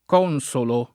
consolo [ k 0 n S olo ]